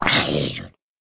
sounds / mob / zombie / hurt2.ogg
hurt2.ogg